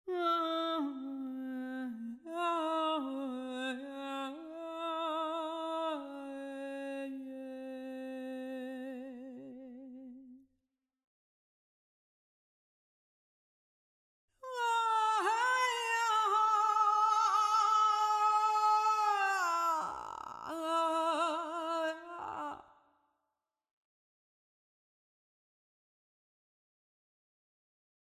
dieses Mal mit angepassten Gates:
Auch den Kompressor für das Front-Mikrofon habe ich an die Lautstärke angepasst, und zwar so, dass der zweite Teil, in dem sie lauter singt, stark komprimiert wird, der erste gar nicht.
Es zeigt sich, dass dieses Preset sich ganz hervorragend für Lead-Vocals eignet und die Dramaturgie des Gesangs steigert: Durch das Zusammenspiel von Kompressor, Gates und Raummikros wirken leise Passagen (etwa Stophen) nah und intim, laute Passagen (Refrain) erobern den Raum und klingen dadurch etwas dramatischer. Der Pegel bleibt dabei angenehm stabil.